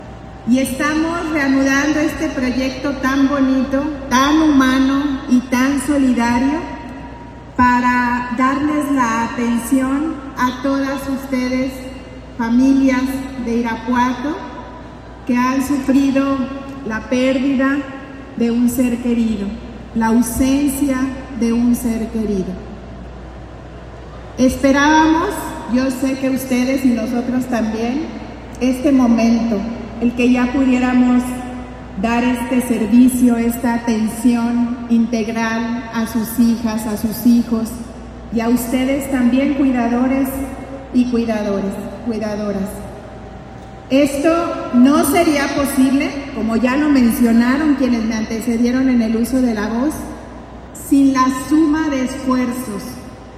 AudioBoletines
Lorena Alfaro García, presidenta de Irapuato